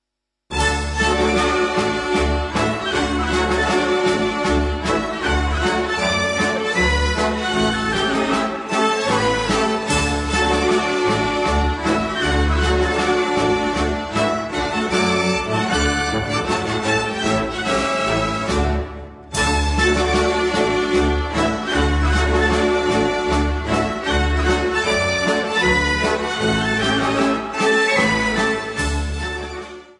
(Nagrania archiwalne z lat 1962-1974)